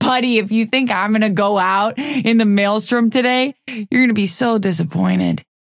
Vyper voice line - Buddy, if you think I'm gonna go out in the Maelstrom today, you're gonna be so disappointed.